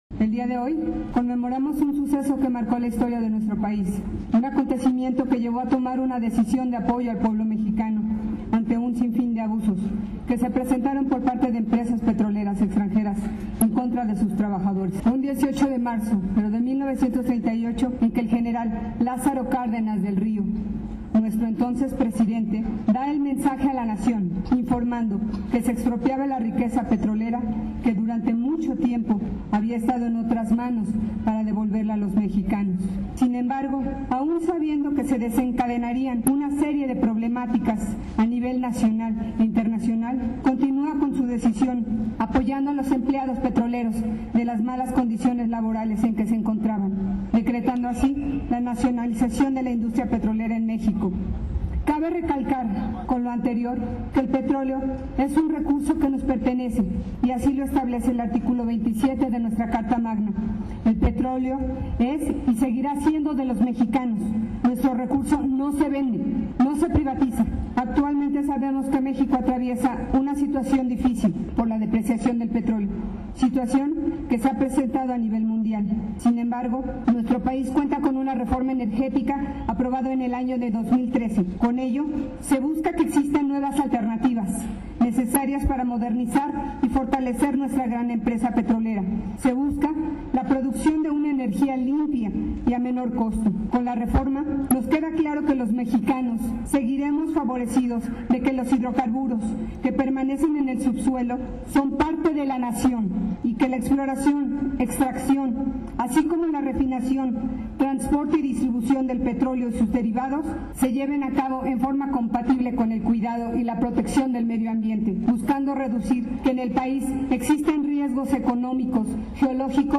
Con motivo del aniversario de la expropiación petrolera se realizó el pasado viernes un acto cívico dentro del cual el discurso oficial corrió a cargo de la regidora Sofía Jaqueline León Hernández quien dijo que el petróleo es un recurso que nos pertenece y no se privatiza pues así lo establece nuestra carta magna y de las nuevas reformas energéticas que están destinadas a mejorar esta área.